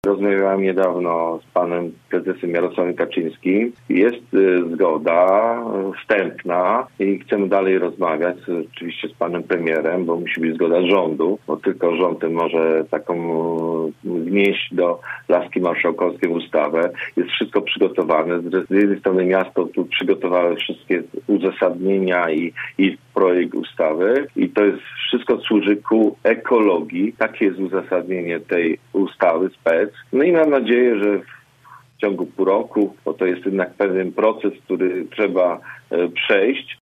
To pytanie zadaliśmy dziś w „Rozmowie po 9” posłowi PiS Jerzemu Materenie.